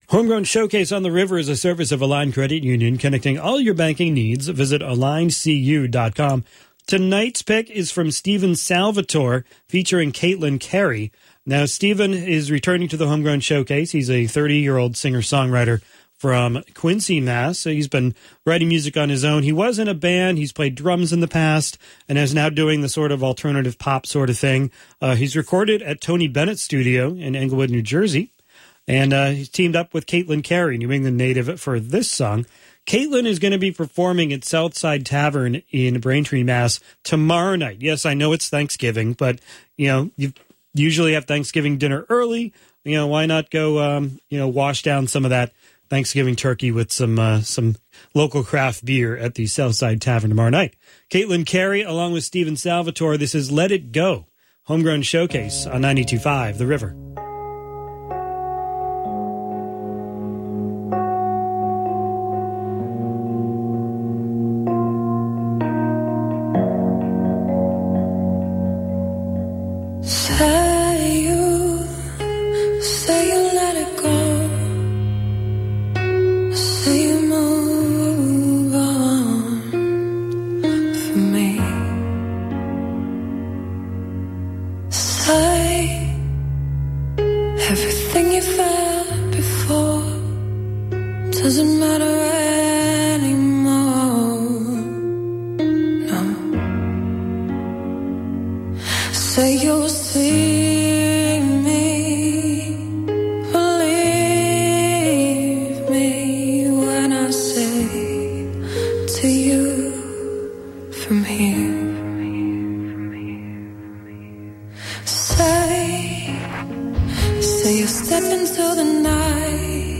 Piano/mellotron
Stings and percussion/drum programming
Acoustic and electric guitar
Vocals